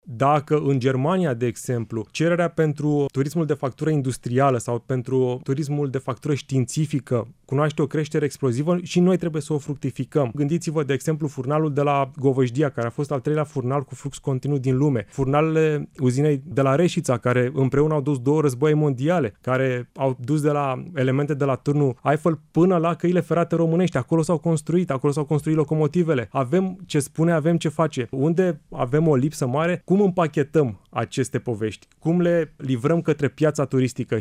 Invitat la Radio România Actualităţi”